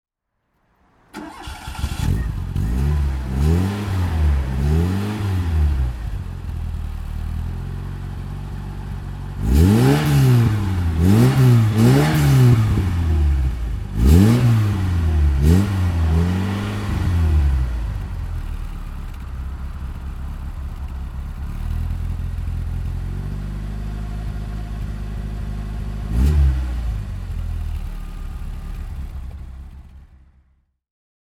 Fiat 128 3P Berlinetta (1977) - Starten und Leerlauf